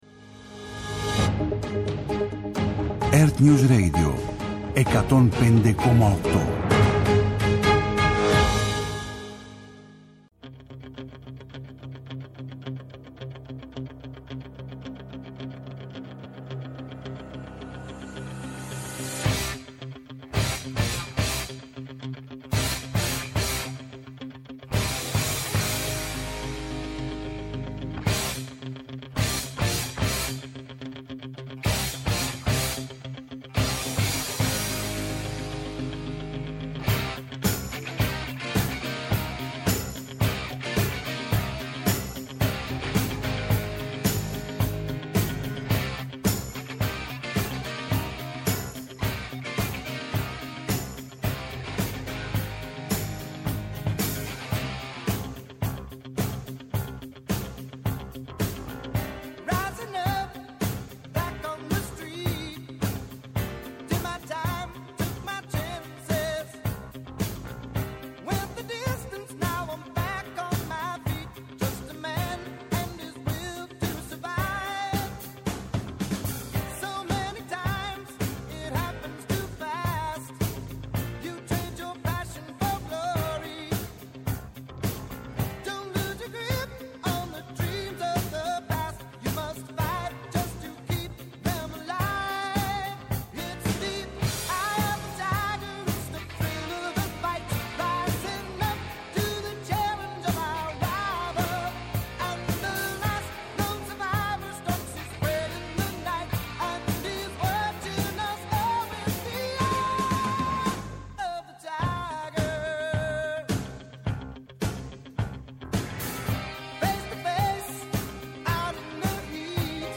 Όλη η επικαιρότητα, με αναλύσεις, ρεπορτάζ, αποκλειστικές συνεντεύξεις και μοναδικά μουσικά αφιερώματα… ΕΡΤNEWS RADIO